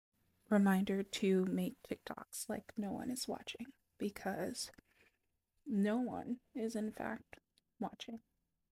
Real White Sounds In My Sound Effects Free Download